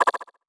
Message Bulletin Echo 9.wav